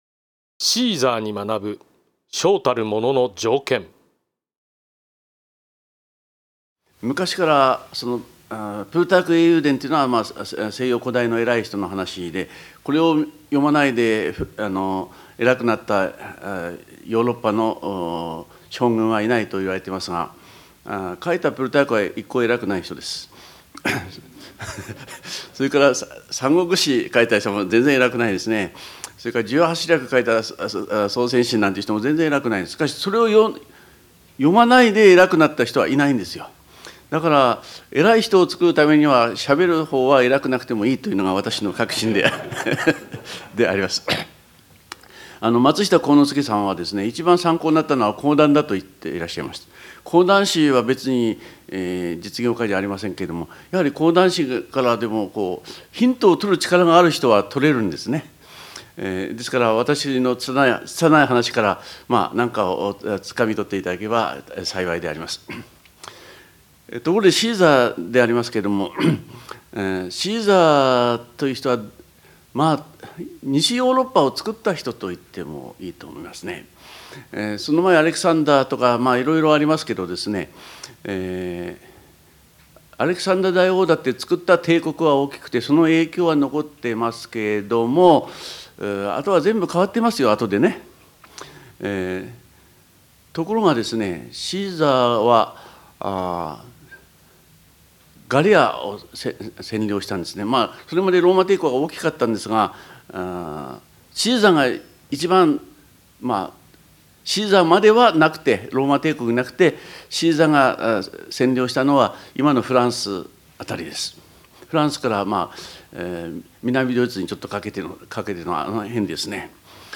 ※この音声は平成20年に開催された致知出版社主催の「渡部昇一 リーダーのための人間学講座『名将に学ぶ成功哲学』」での講演を収録したものです。